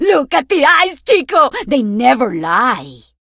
diva_start_vo_01.ogg